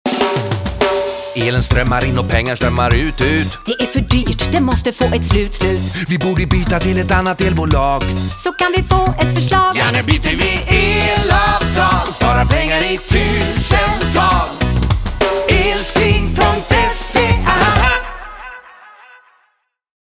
Hajp producerar Elsklings radiokampanjer.
Elskling-Reggae-20s-Strommar.mp3